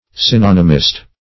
What does synonymist mean?
Search Result for " synonymist" : Wordnet 3.0 NOUN (1) 1. a student of synonyms ; The Collaborative International Dictionary of English v.0.48: Synonymist \Syn*on"y*mist\ (s[i^]n*[o^]n"[i^]*m[i^]st), n. [Cf. F. synonymiste.]